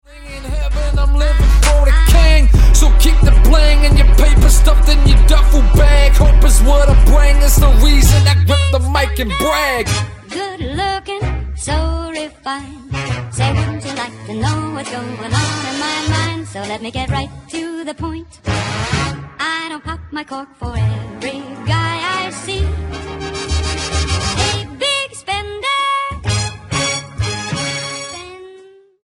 The Scottish rapper
Style: Hip-Hop